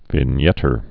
(vĭn-yĕtər)